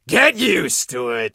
monster_stu_kill_vo_02.ogg